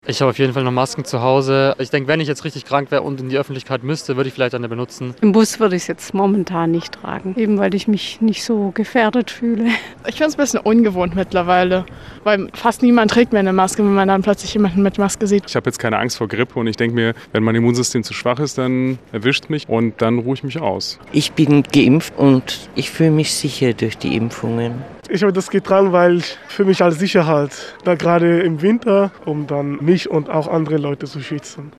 Umfrage in Ulm